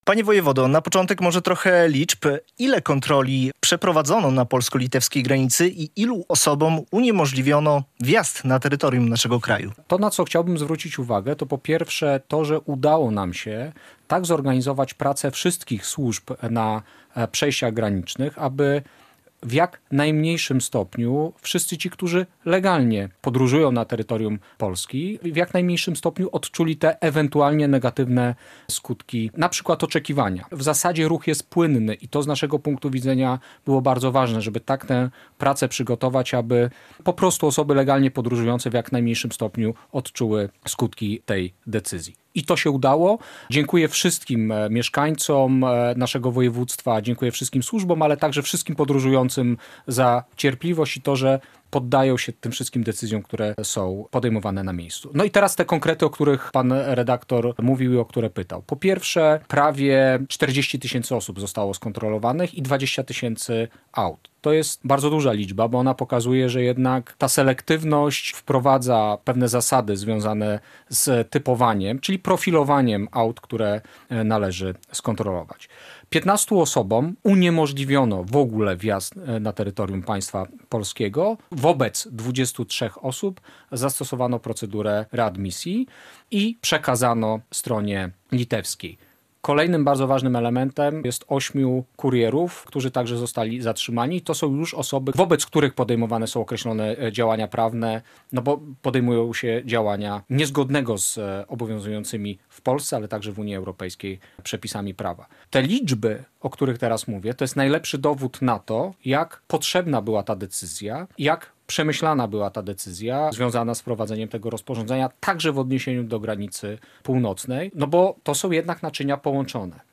Z wojewodą Jackiem Brzozowskim rozmawia